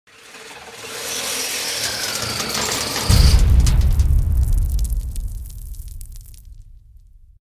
anchor.ogg